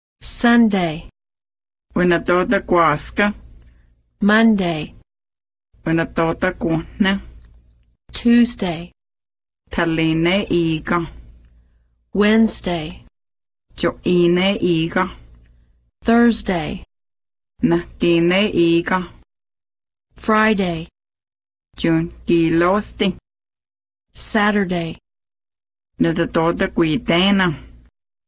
Cherokee Audio Lessons